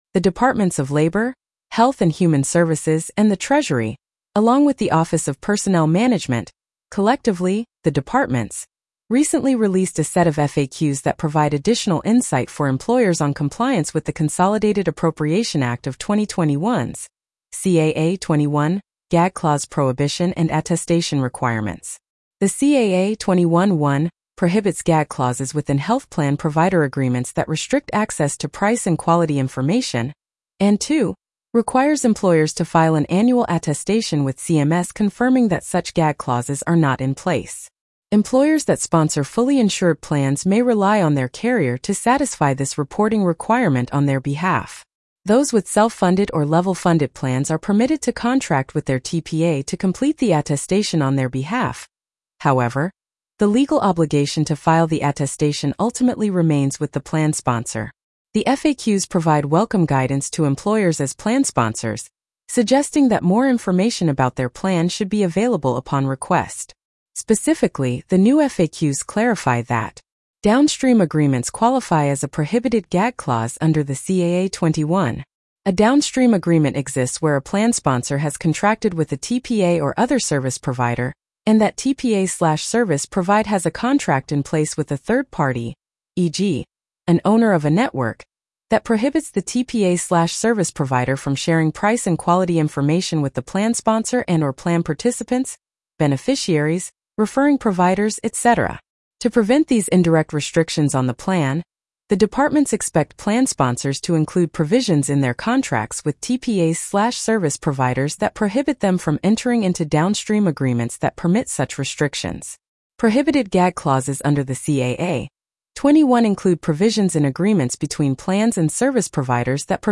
Additional Gag Clause Prohibition and Attestation Compliance Guidance Released Blog Narration.mp3